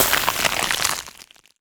ice_spell_freeze_frost_04.wav